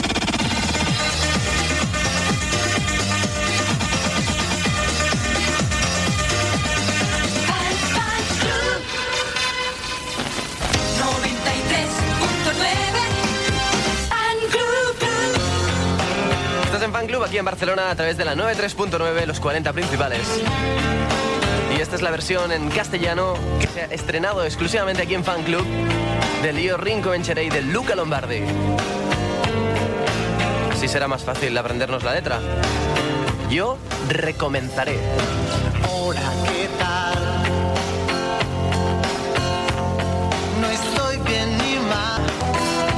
Jingle "Fan club". Indicatiu 93,9. Presentació cançó.
Musical
FM